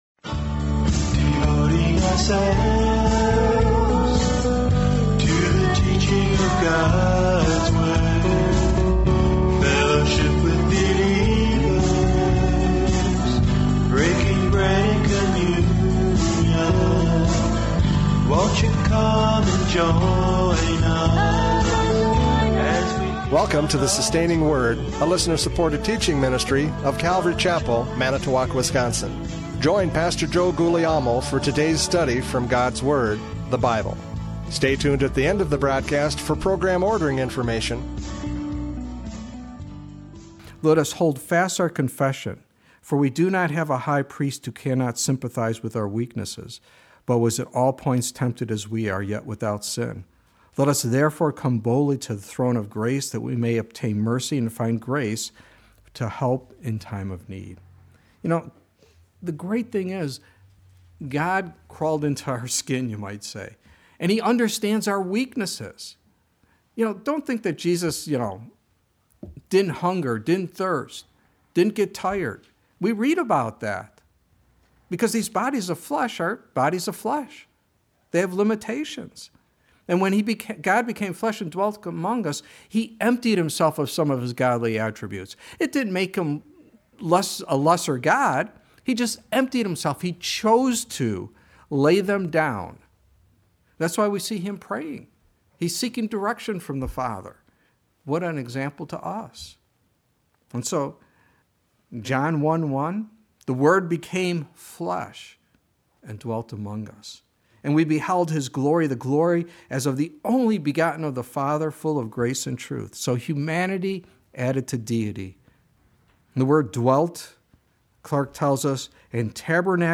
Hebrews 10:5-10 Service Type: Radio Programs « Christmas 2024 Hebrews 10:5-10 Tour of Duty!